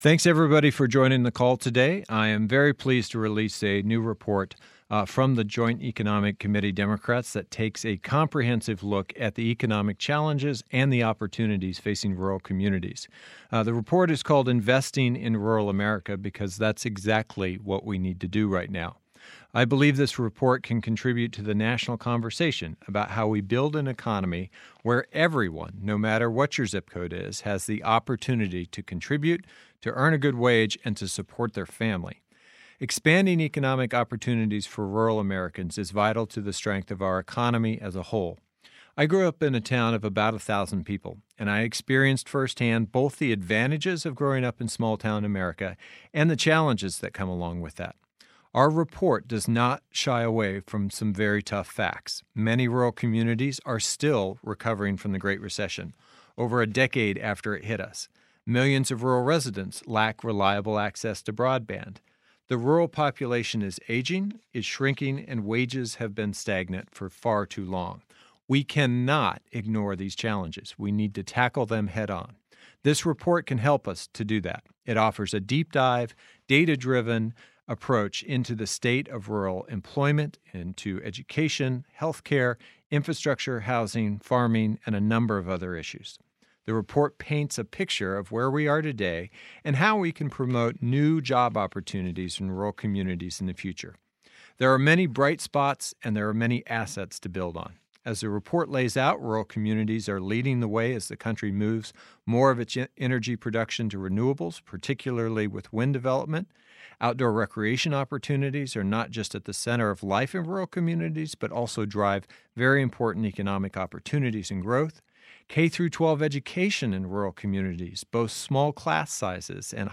Heinrich Remarks at Press Teleconference on JEC Dems Rural Economy Report
WASHINGTON, D.C. U.S. Senator Martin Heinrich (D-N.M.), Ranking Member of the Joint Economic Committee, delivered the follow remarks at a press teleconference today unveiling the release of a new JEC Democrats report, “Investing in Rural America,” that examines the current state of the rural economy. In his remarks, Senator Heinrich emphasized the importance of working to revitalize rural economies that have not fully recovered from the Great Recession, and highlighted the opportunities for growth in these communities.